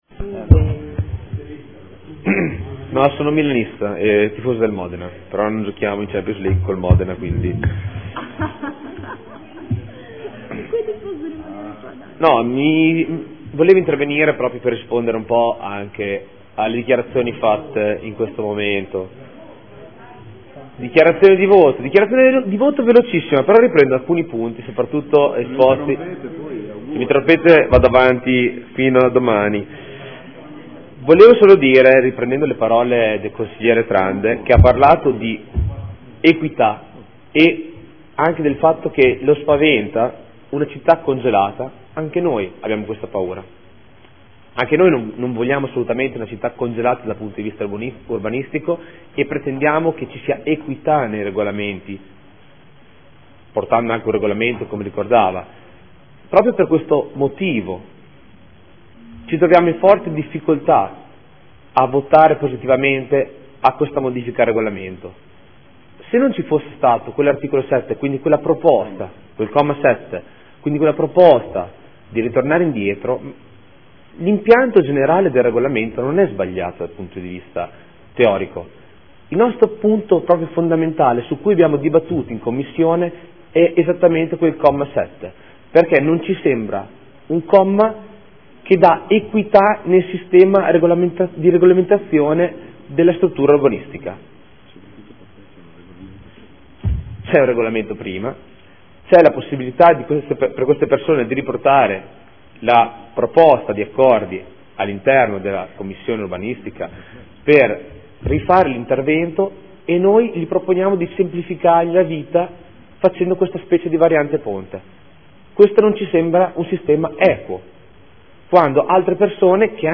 Proposta di deliberazione: Modifiche al Regolamento contenente i criteri e le modalità applicative dell’articolo 14.1 del RUE approvato con deliberazione di Consiglio comunale del 16.6.2008 e successive modificazioni. Dichiarazione di voto